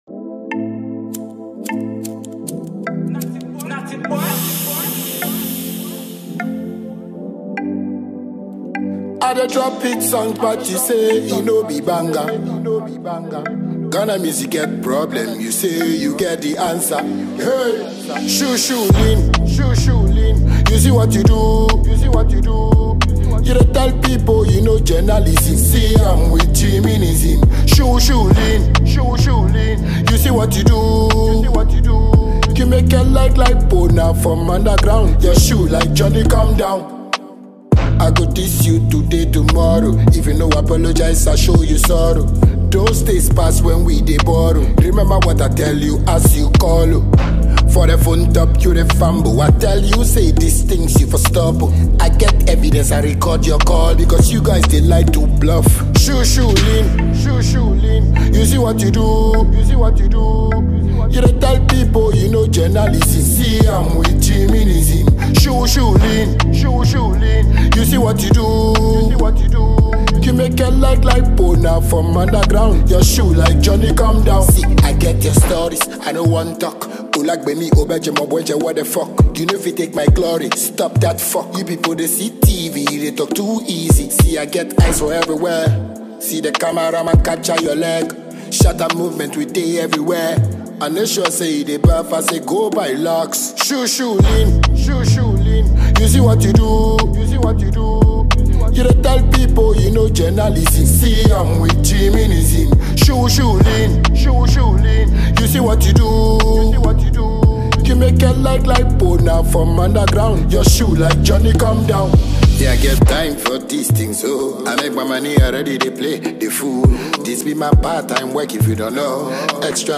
Renowned dancehall artist
pulsating beats and infectious rhythms
The fusion of traditional rhythms and contemporary sounds